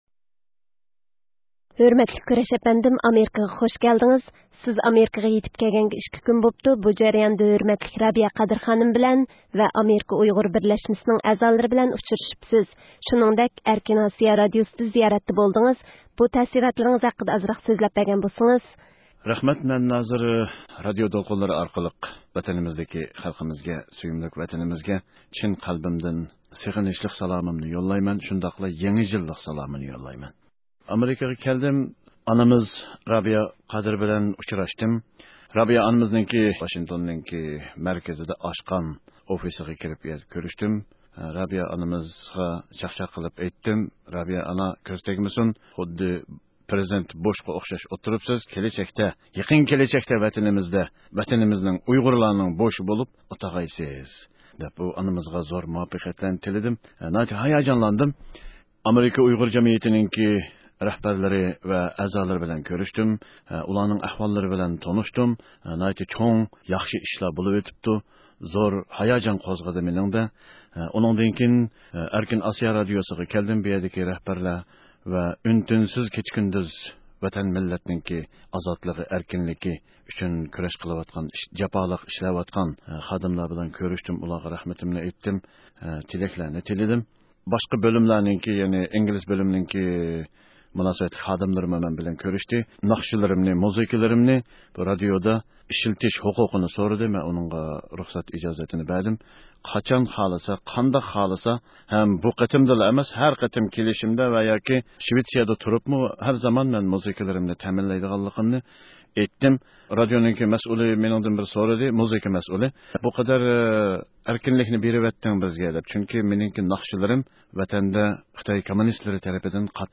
سۆھبەت